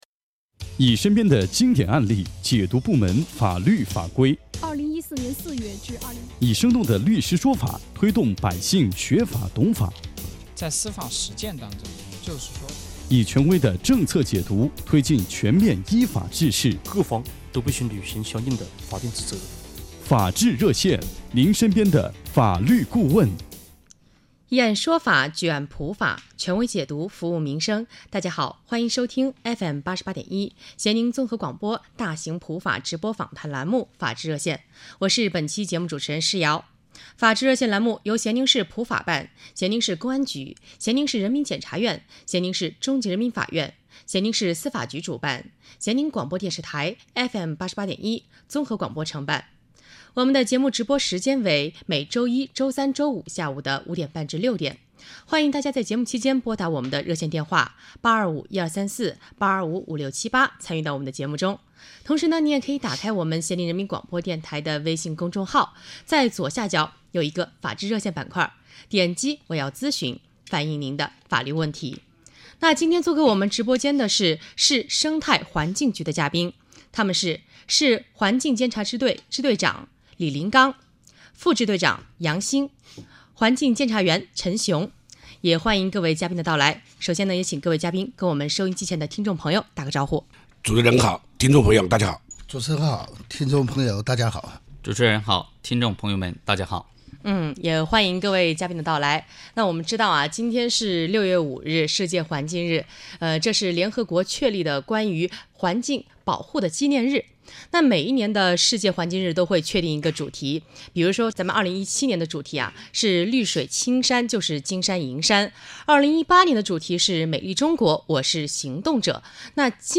6月14日，咸宁市生态环境局再次组织执法干部走进电台直播间，以案说法讲述环保法律法规，接收群众咨询投诉。